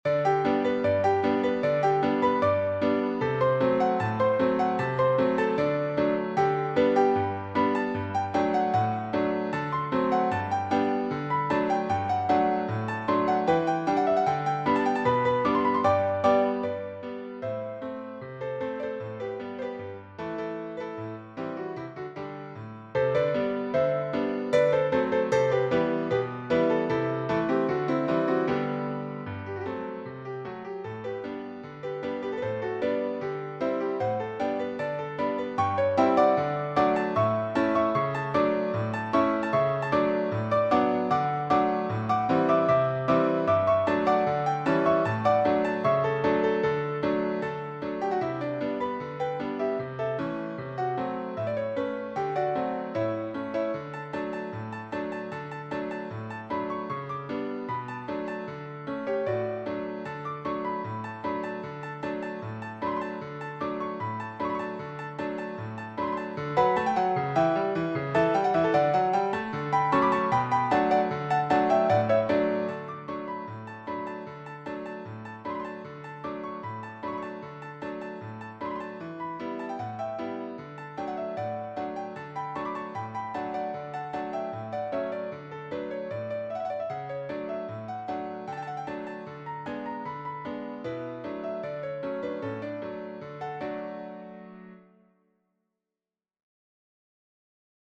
A ragtime arrangement
for solo piano
with playful syncopation and a powerful left-hand stride